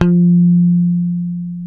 -JP PICK F.4.wav